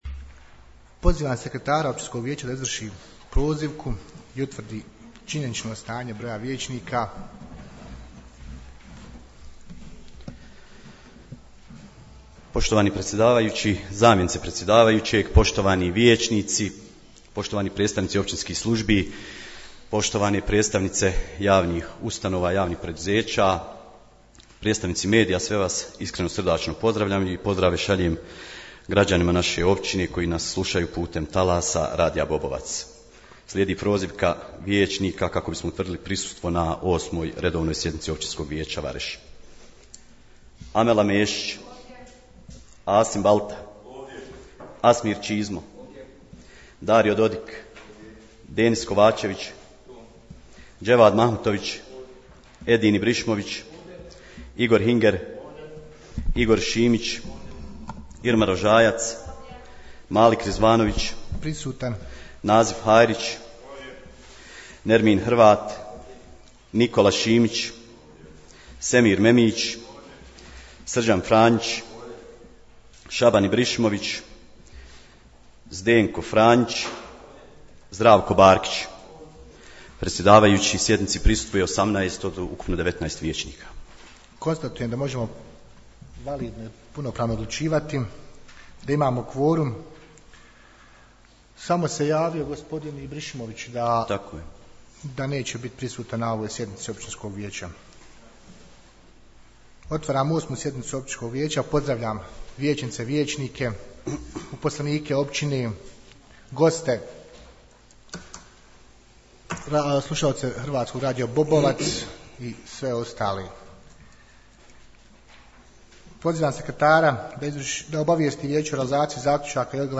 8. sjednica Općinskog vijeća Vareš održana je 30.06.2025. godine na kojoj je bilo dvanaest točaka dnevnog reda, poslušajte tonski zapis .....